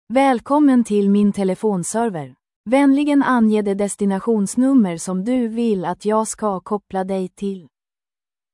Klangbeispiel eines deutschen Textes als MP3: Die Stimme ist bei Google gTTS immer weiblich.
Sprachbeispiel auf Schwedisch: Klingt ganz gut.